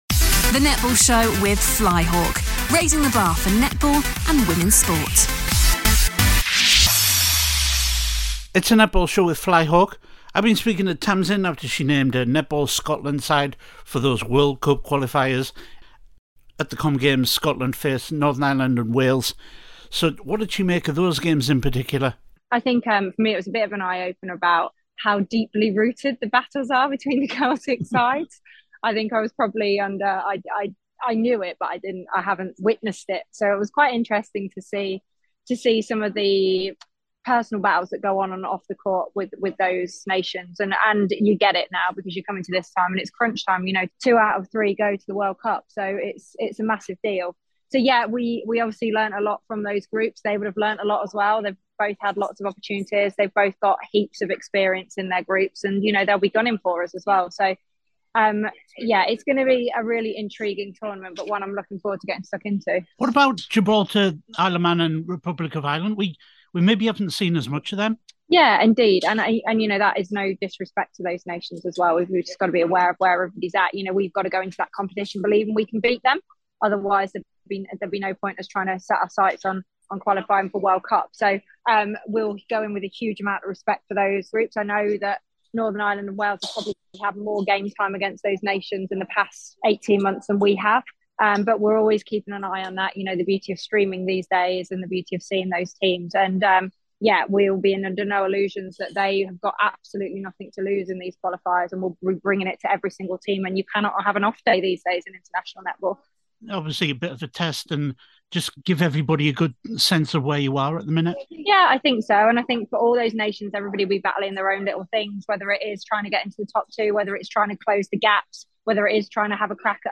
Tamsin speaks ahead of NWCQ